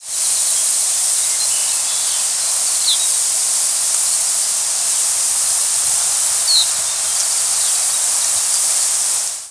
Bicknell's Thrush Catharus bicknelli
Flight call description A high, raspy, descending "vzeer" or "pzeeer".
Diurnal calling sequence:
Perched bird on the breeding grounds with Winter Wren and Swainson's Thrush singing in the background.
Similar species Very similar to Gray-cheeked Thrush but typically higher and more evenly descending (less downward-arched).